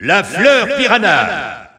Announcer pronouncing Piranha Plant's name in French.
Piranha_Plant_French_Alt_Announcer_SSBU.wav